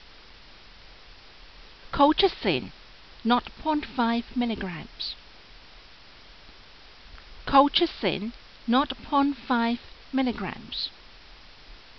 Pronunciation[edit]
Colchicine_0.5mg.mp3